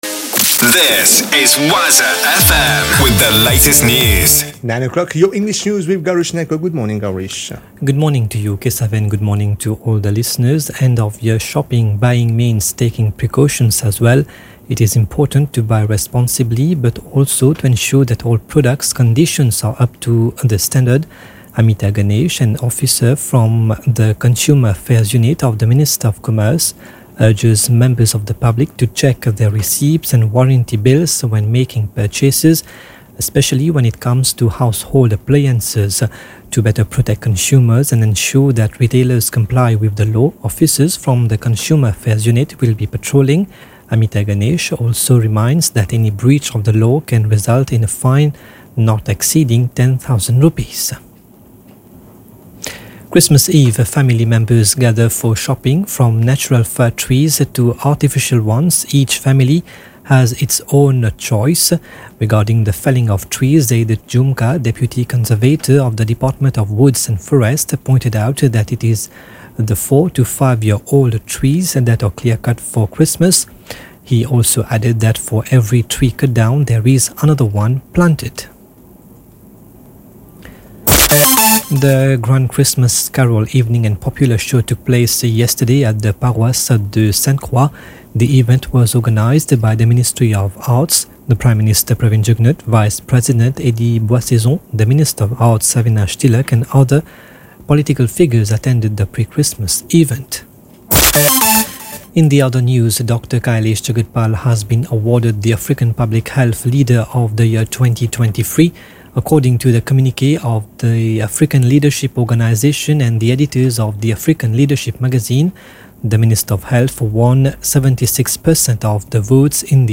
NEWS 9h - 24.12.23